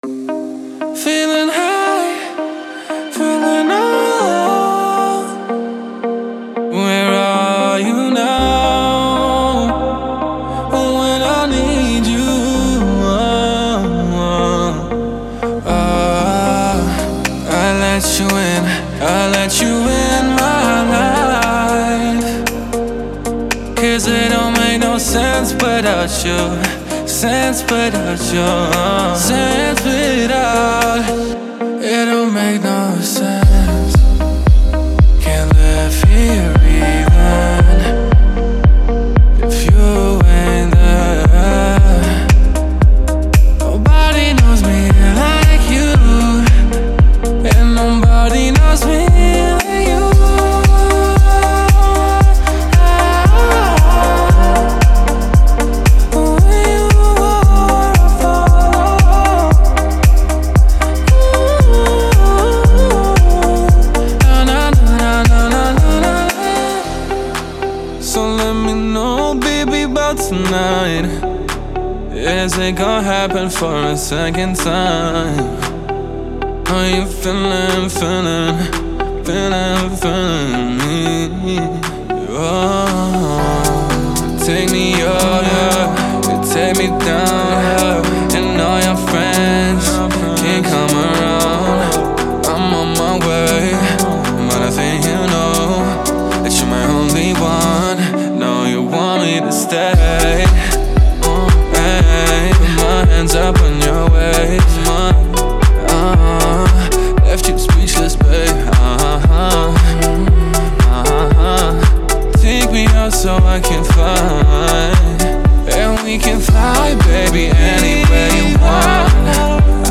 Genre:Deep House
デモサウンドはコチラ↓